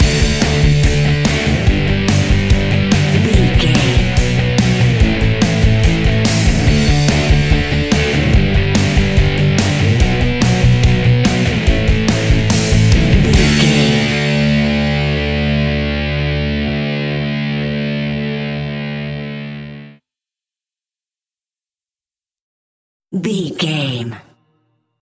Epic / Action
Fast paced
Ionian/Major
D
hard rock
blues rock
distortion
rock guitars
Rock Bass
Rock Drums
distorted guitars
hammond organ